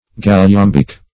Galliambic \Gal`li*am"bic\, a.
galliambic.mp3